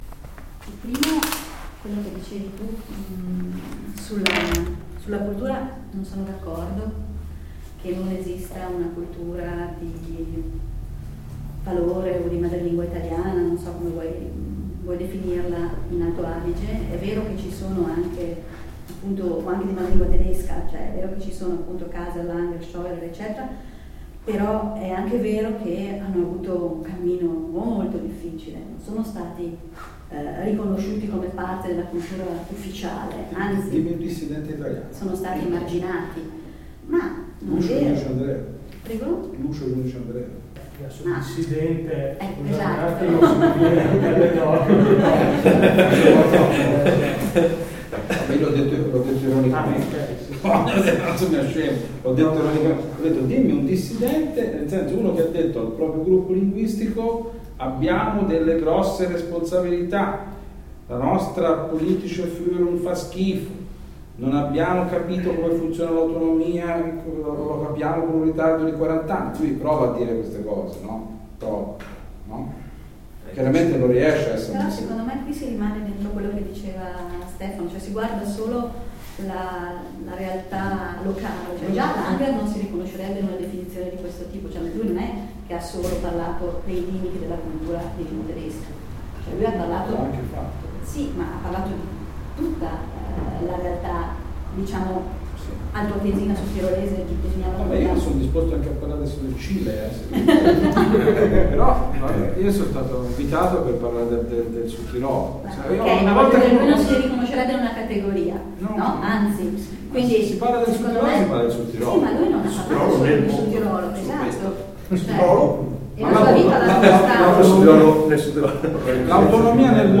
Audioaufnahmen der Diskussion zum Artikel 5